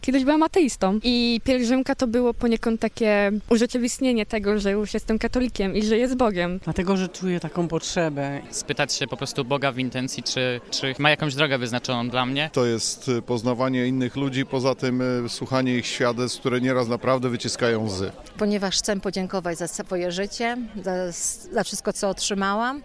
Tegoroczni pielgrzymi opowiedzieli nam o powodach, dla których zdecydowali się w nią wyruszyć.